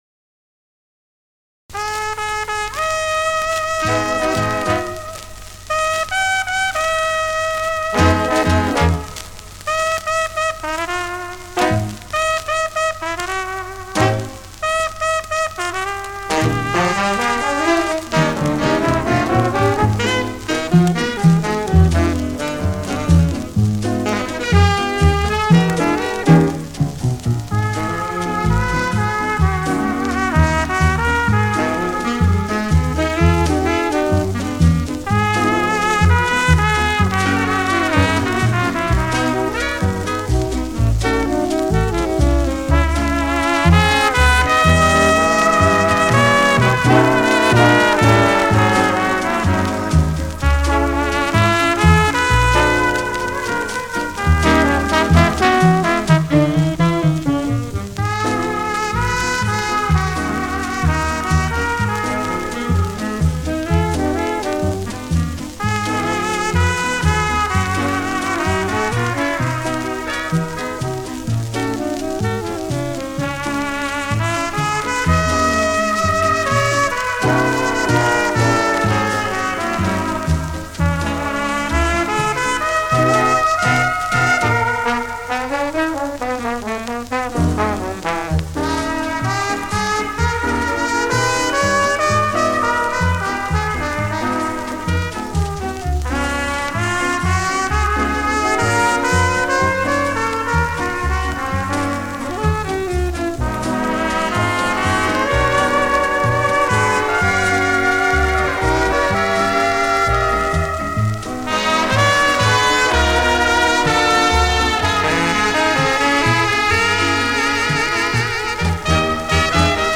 Качество не очень (видно была запиленная пластинка)